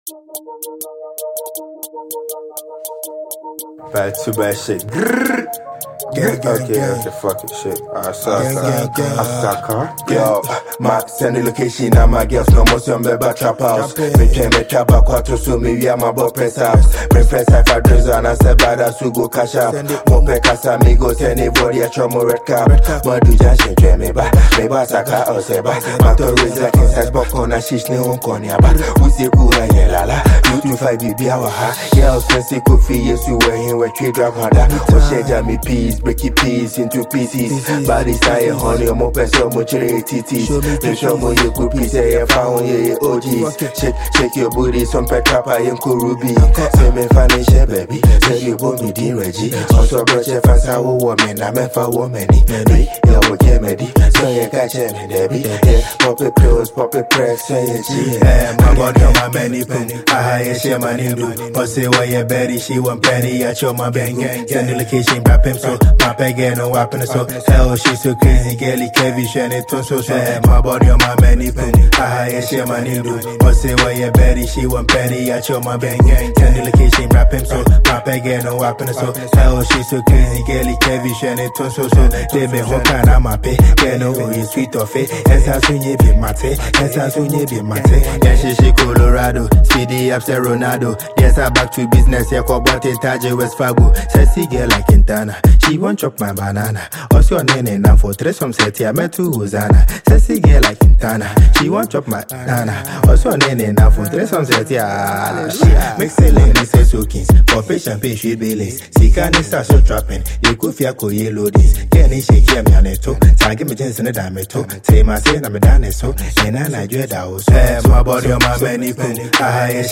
Ghana Music
Asakaa
Gritty and Hypnotic New Single
Kumerican drill movement